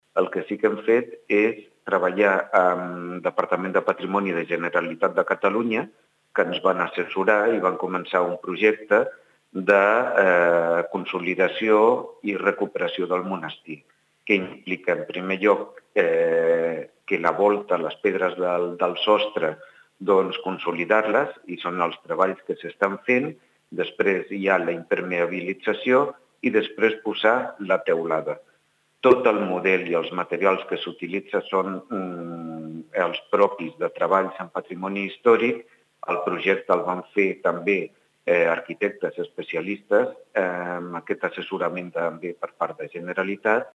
Rafa Delgado, regidor responsable del projecte de rehabilitació de Roca Rossa, ens explica els detalls